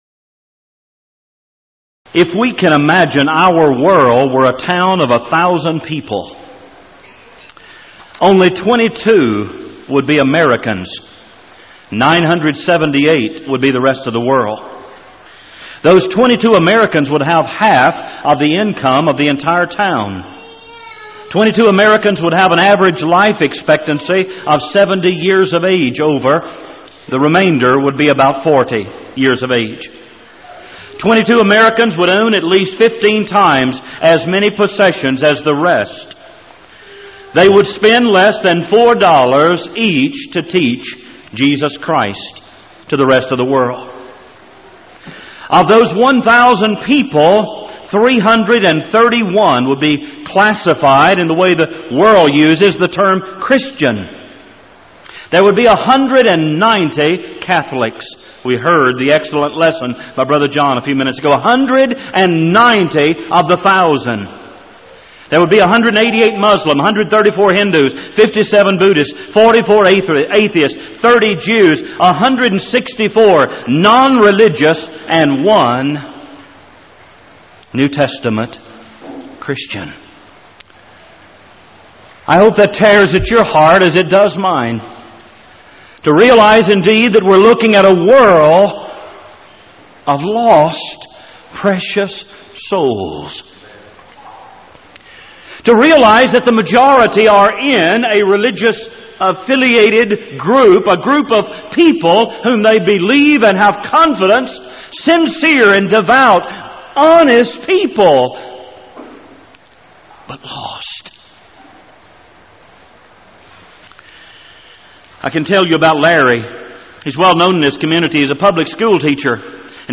Event: 1997 Power Lectures
lecture